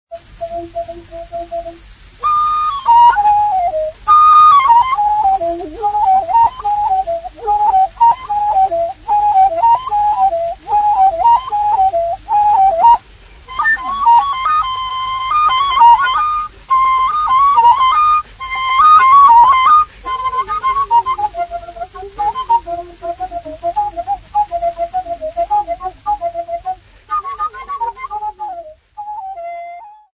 TP3252-XYZ7443b.mp3 of Two flute songs